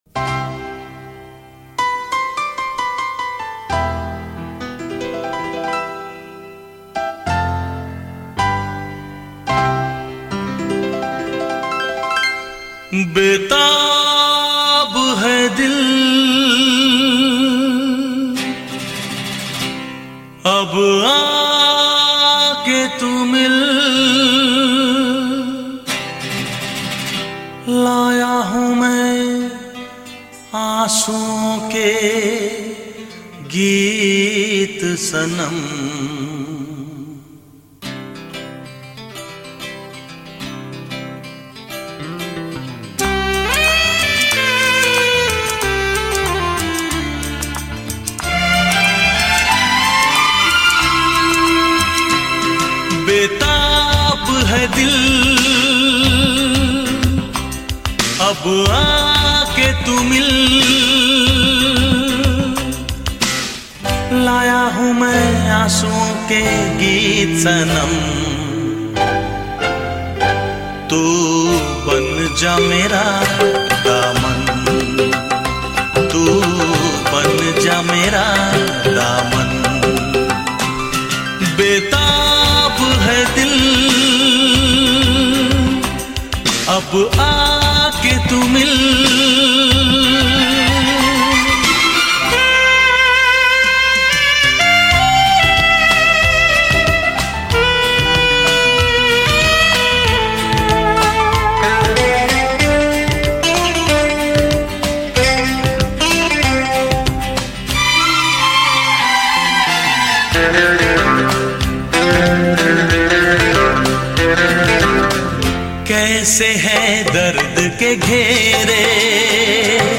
Hindi Romantic Hits